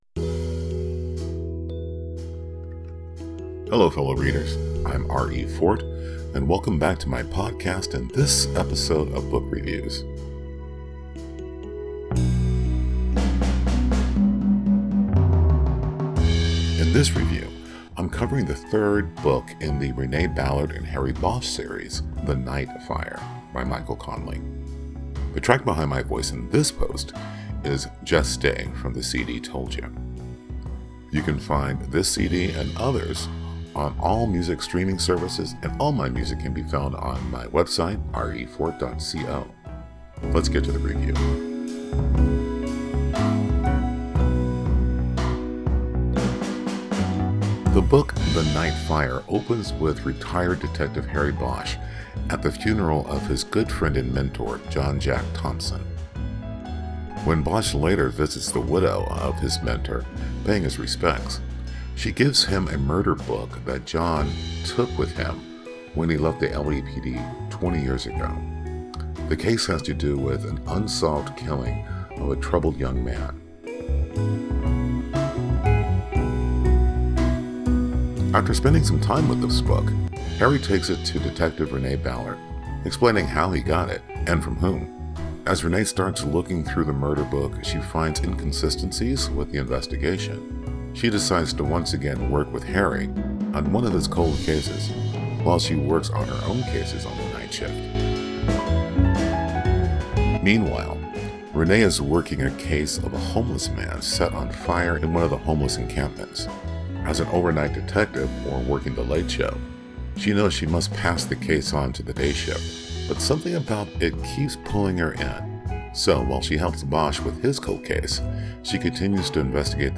Book Review: The Night Fire